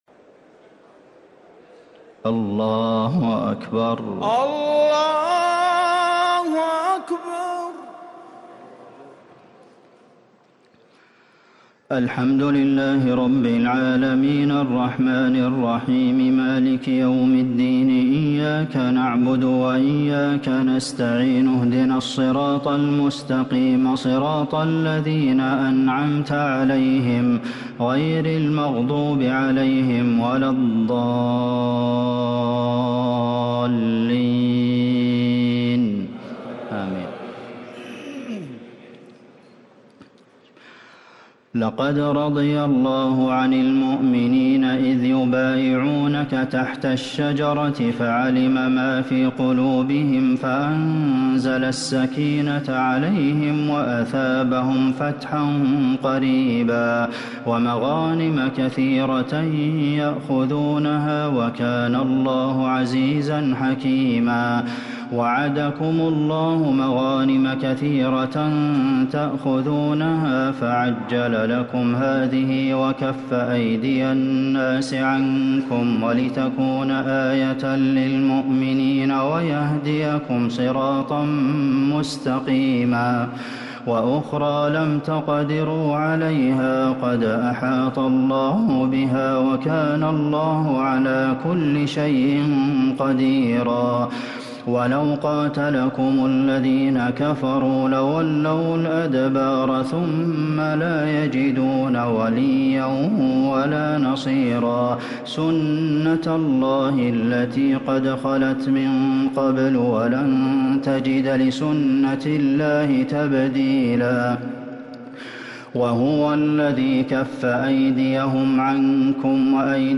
تراويح ليلة 27 رمضان 1444هـ من سور الفتح (18-29) و الحجرات و ق | Taraweeh 27 st night Ramadan 1444H Surah Al-fath And Al-Hujuraat Qaf > تراويح الحرم النبوي عام 1444 🕌 > التراويح - تلاوات الحرمين